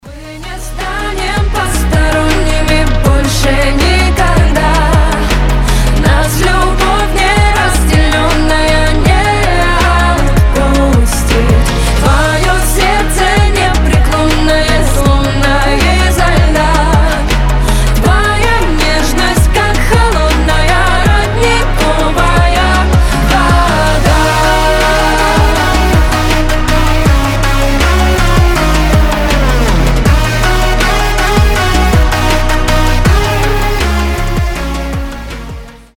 • Качество: 320, Stereo
женский голос
чувственные